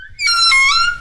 DoorOpen.ogg